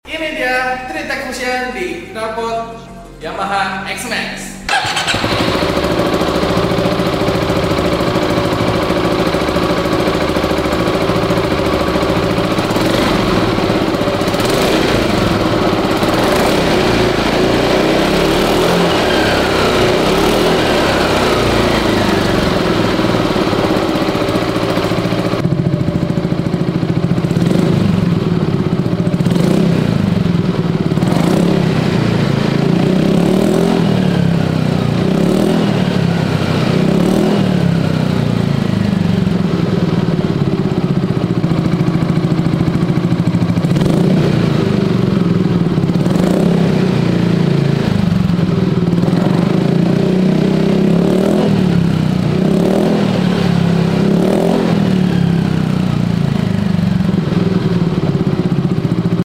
3Tech Fusion Yamaha Xmax #3techracingevolution sound effects free download